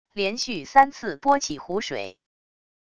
连续三次拨起湖水wav音频